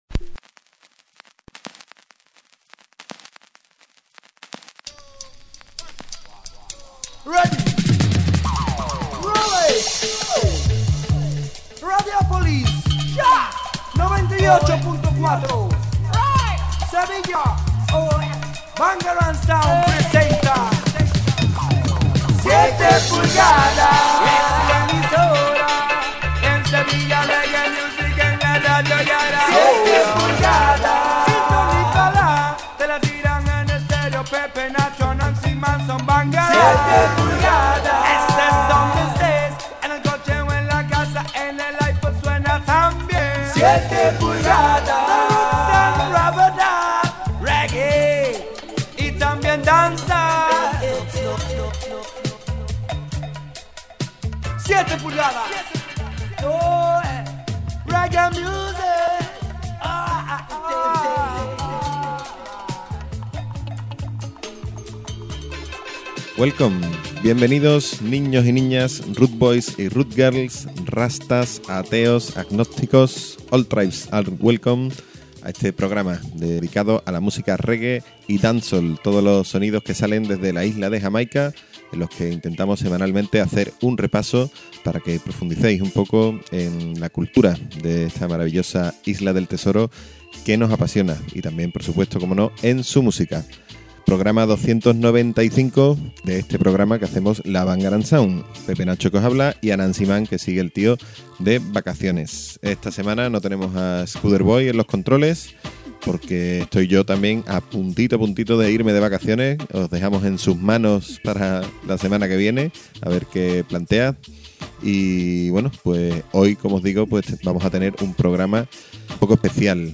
Mixtape Dancehall